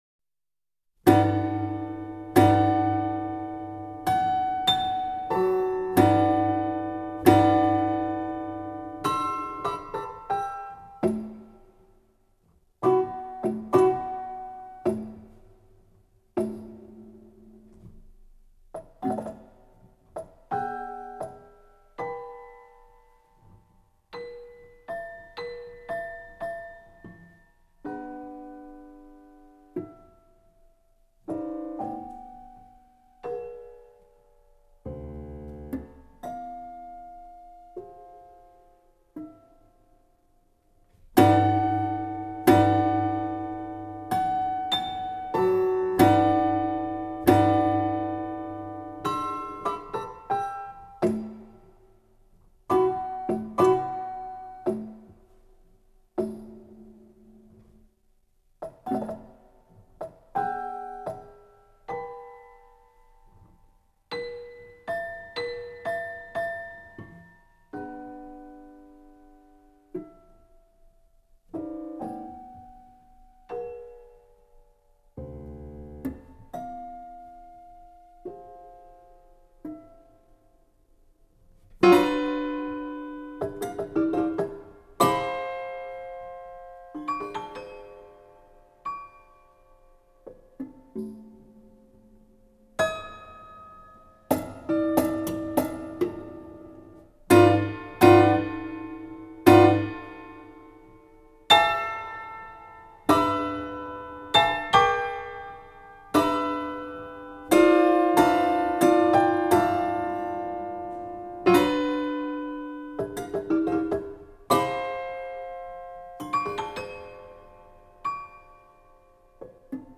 Prepared Piano
Indeed, in his hands, the prepared pinoforte transformed into a piano-percussion ensemble with sounds ranging from the marimba to small drums, to the piano proper, passing through the metallic sounds of untuned bells whose tolling is dedicated not to a God, but to Nothingness.
Finally, it should be kept in mind that the insertion of objects alters the tension of the strings between which they are inserted more or less slightly, thus also producing slight detuning effects.
Notice that the correspondence between sound and sign no longer exists.
John Cage (1912-1992), Sonatas and Interludes (1946-48) for prepared piano